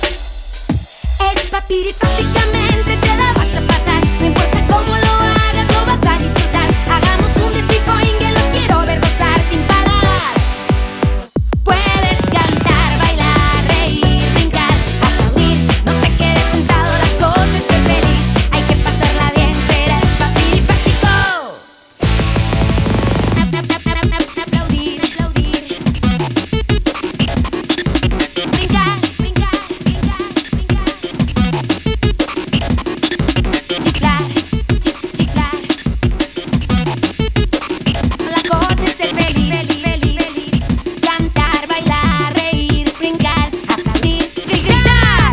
In 2007, she released another new children's album.
(remix)